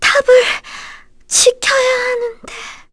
Dosarta-Vox_Dead_kr.wav